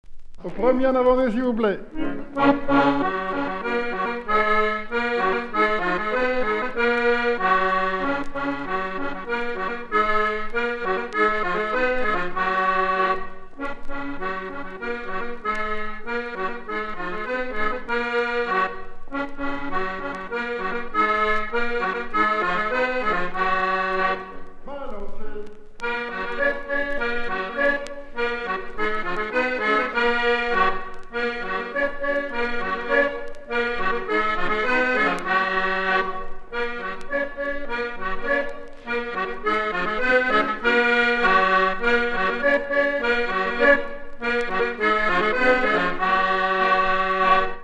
Avant-deux
Résumé instrumental
danse : branle : avant-deux
Pièce musicale inédite